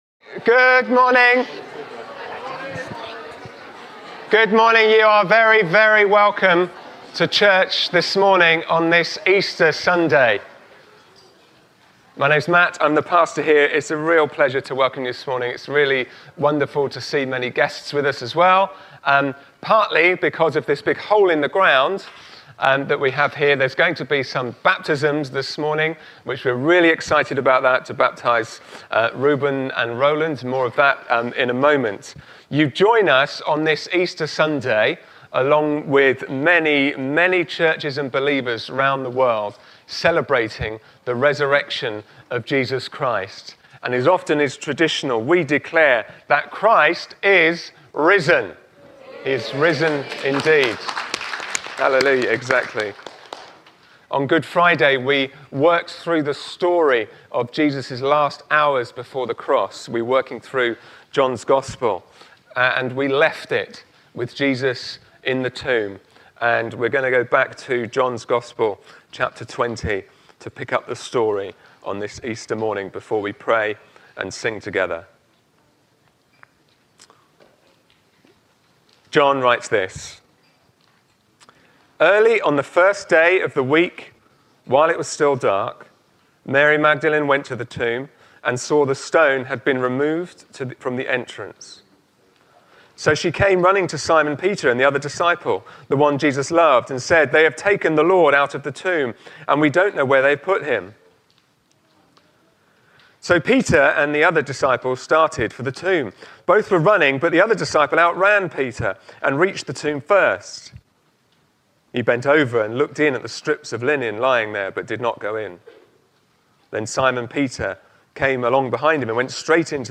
All-Age Easter Celebration & Baptism Service